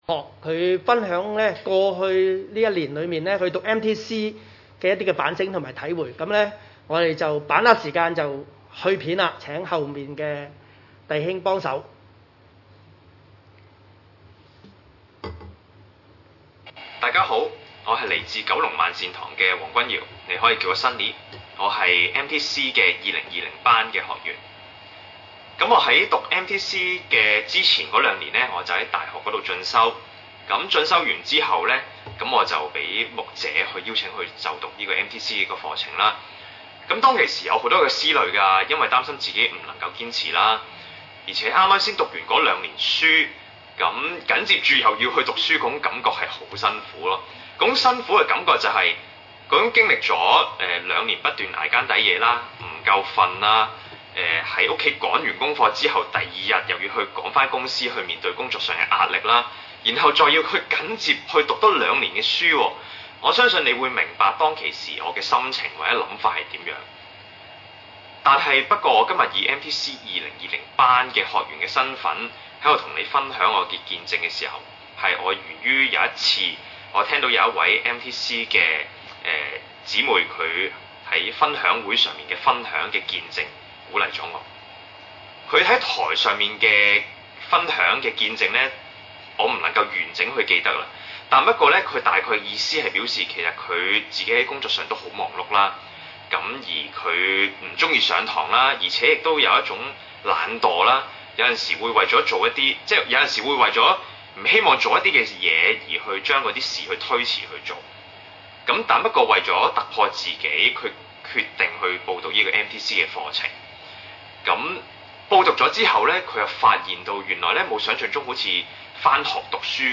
28-29 崇拜類別: 主日午堂崇拜 28 耶穌講完了這些話，眾人都希奇他的教訓； 29 因為他教訓他們，正像有權柄的人，不像他們的文士。